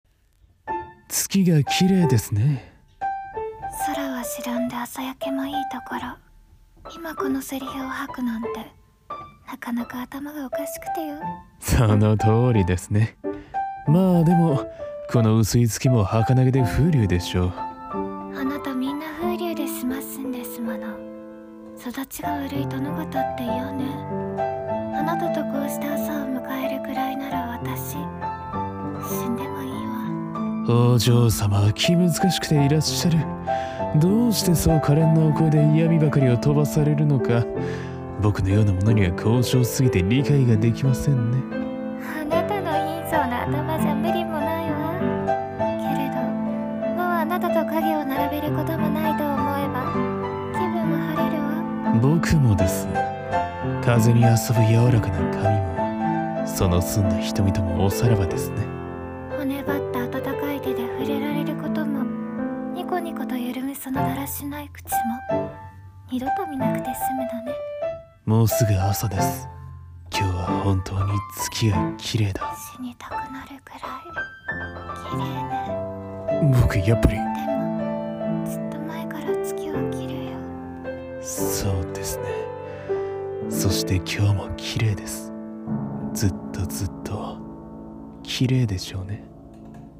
【声劇台本】月の浮かぶ朝